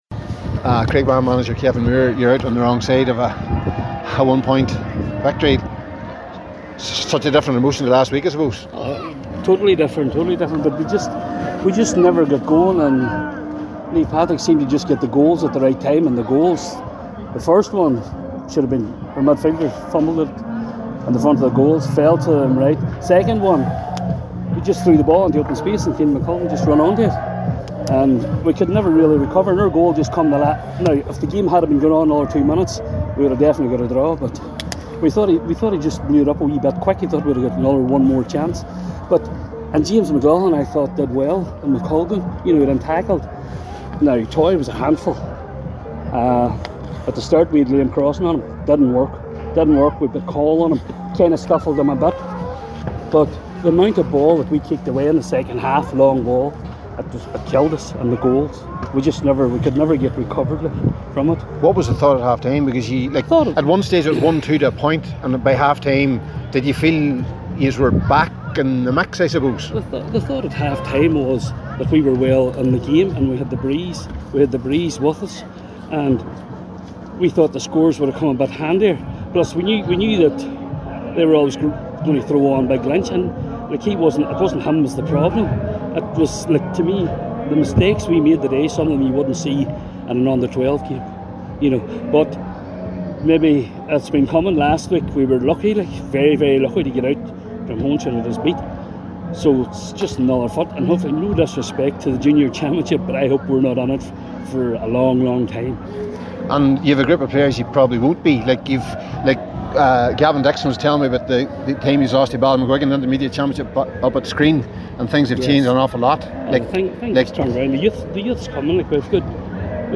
Reaction
after the game…